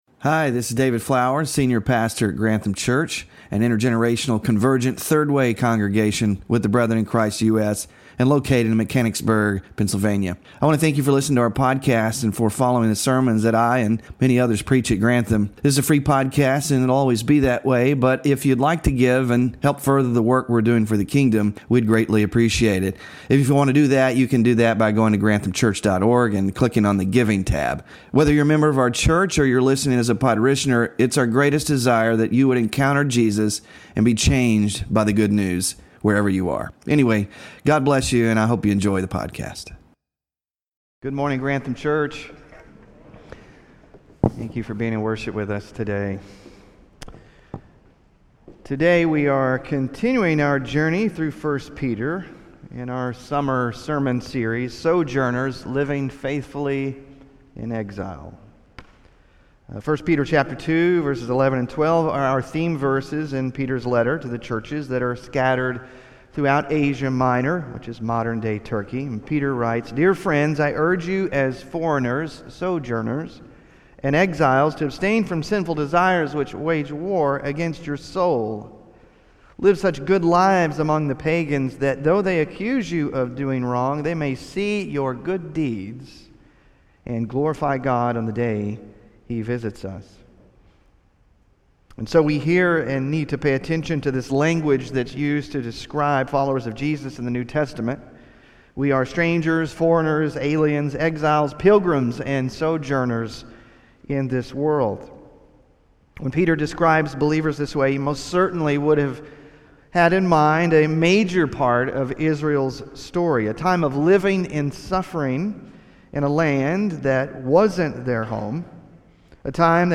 SOJOURNERS SERIES Scripture Reading: 1 Peter 4:12–19; Daniel 3:16-18; Matthew 5:11-12; Romans 8:18 Sermon Focus: Peter says that Christians should not be surprised by trials—we should expect them.